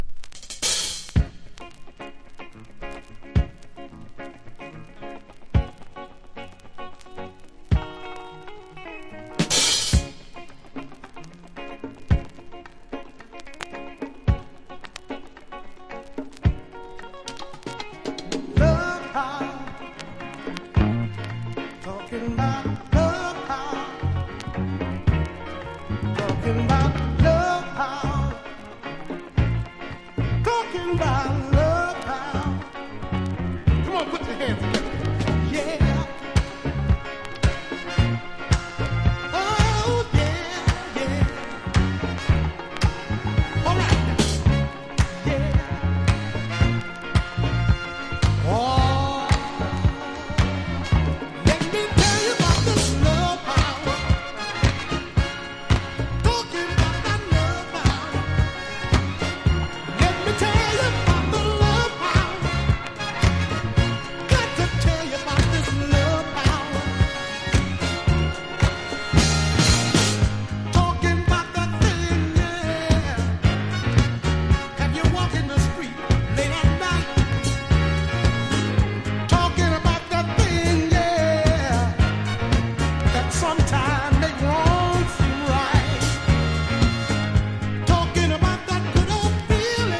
# FUNK / DEEP FUNK